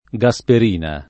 [ g a S per & na ]